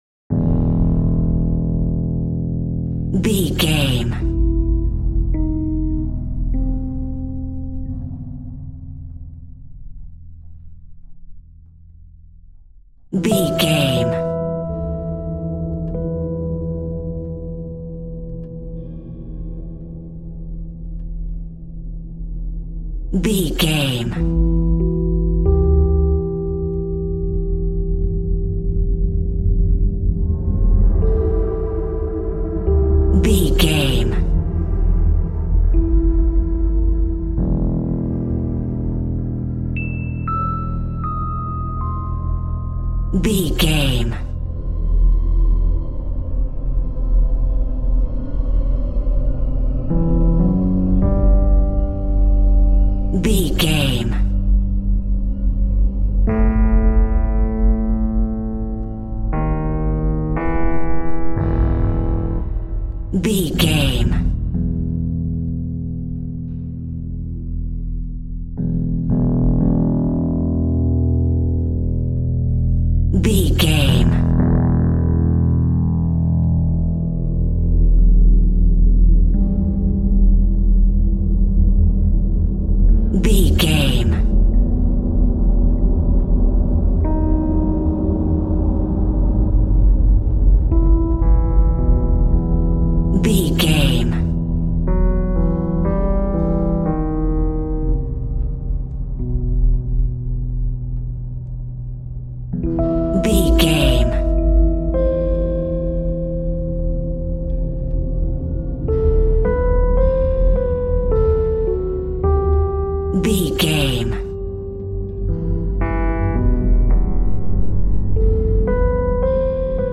Scary Whispers.
Aeolian/Minor
Slow
tension
ominous
dark
eerie
electric piano
synthesiser
ambience
pads